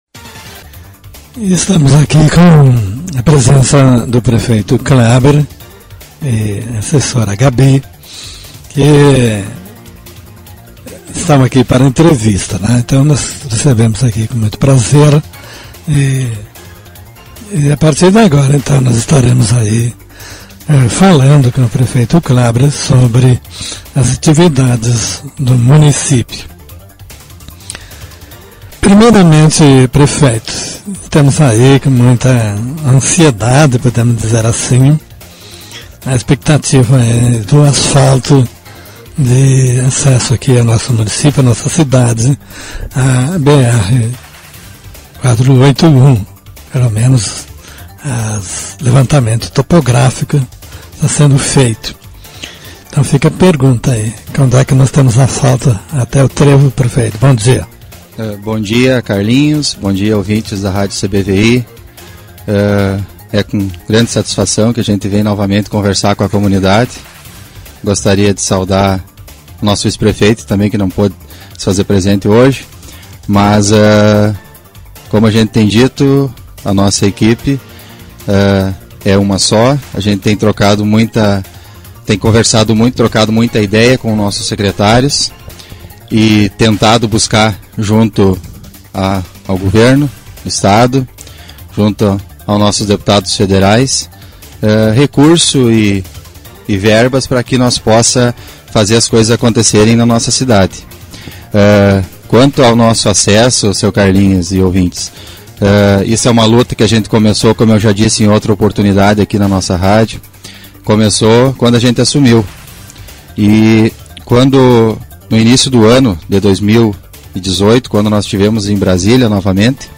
Entrevista Prefeito Cleber sobre o Acesso Asfálti... mp3 Publicado em 17/09/18 Formato: audio/mpeg